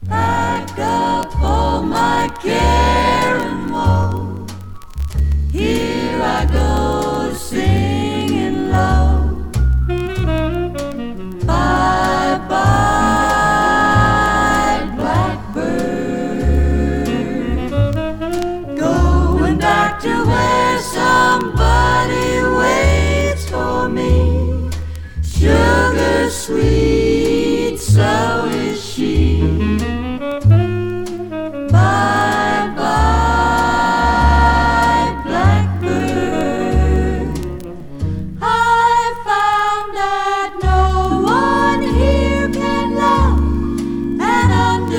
男女混声ヴォーカルグループ
Jazz, Pop, Vocal, Easy Listening　USA　12inchレコード　33rpm　Stereo